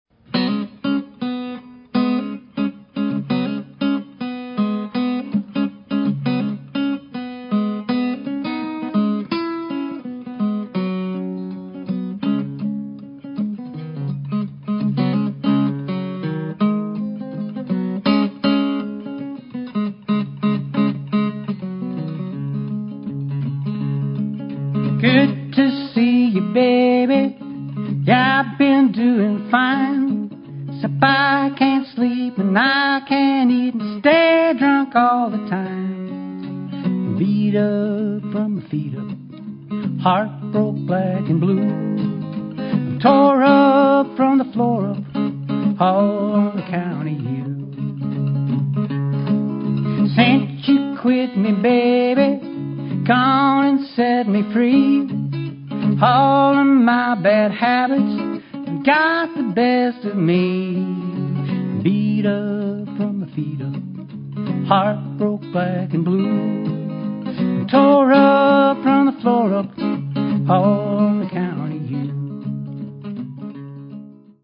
recorded live in front of an audience